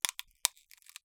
ice crack 28.wav